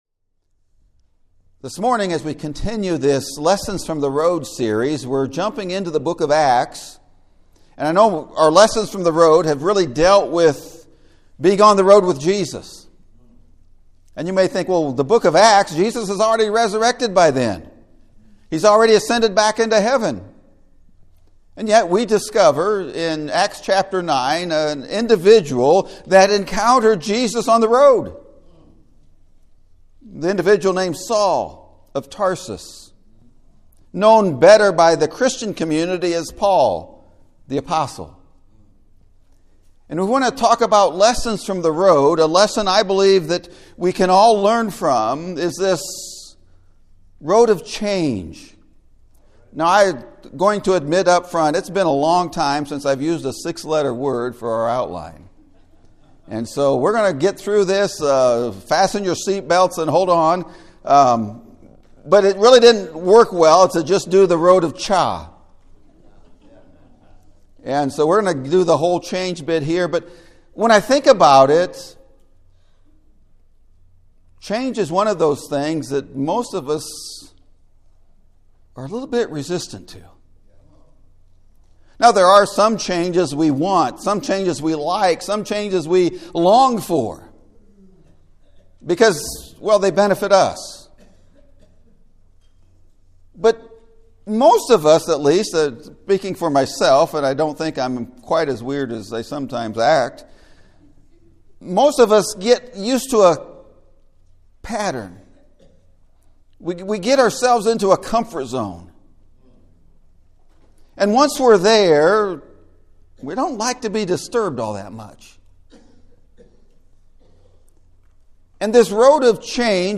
Lessons From the Road: A Road of CHANGE! (Sermon Audio)